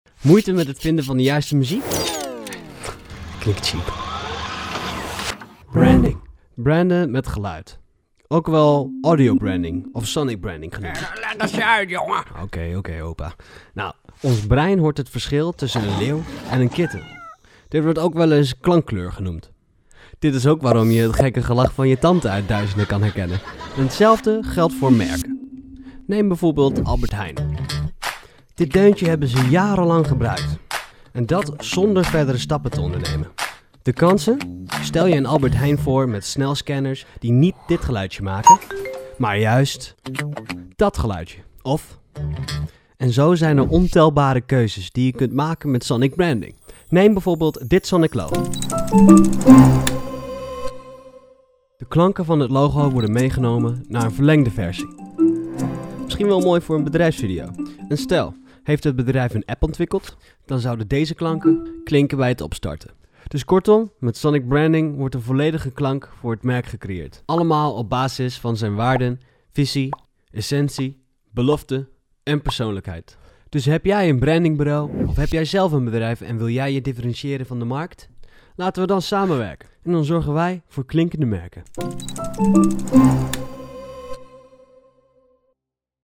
Voiceover-SF.mp3